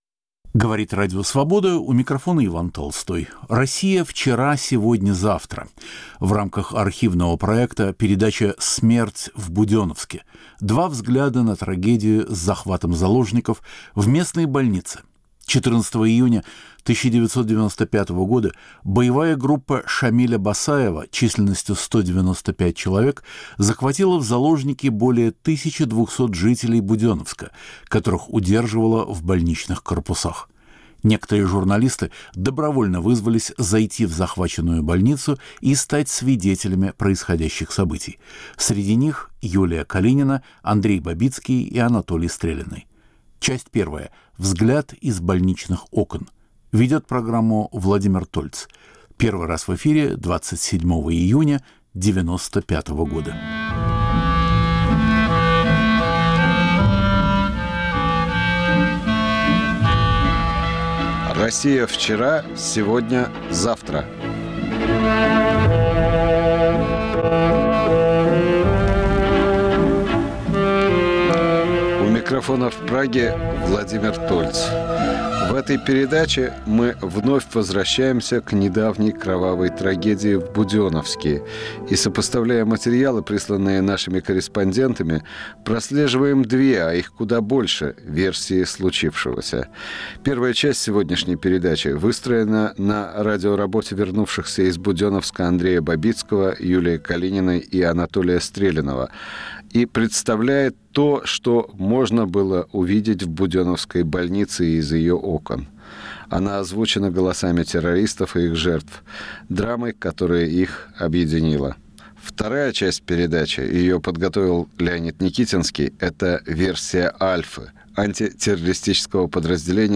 Звучат голоса пациентов, врачей, захватчиков. Второй взгляд - "версия Альфы": интервью с представителями группы освобождения заложников.